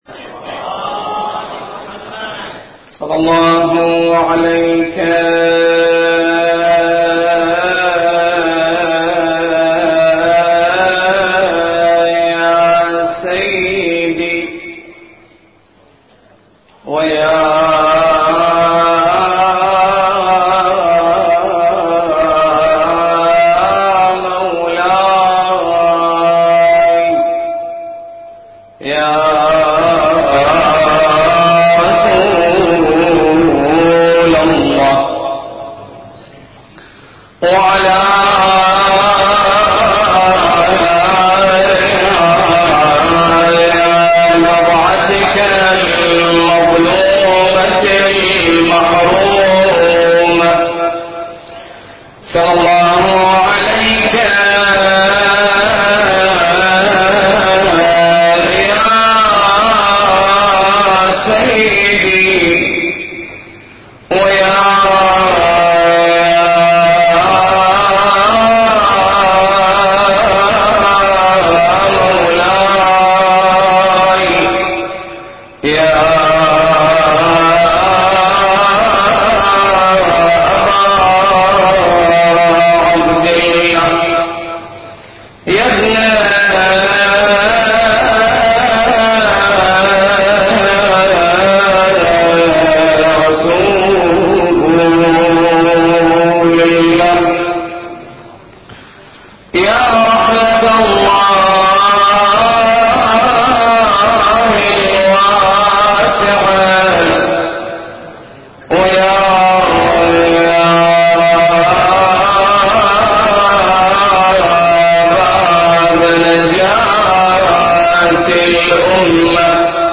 المحاضرات الدينية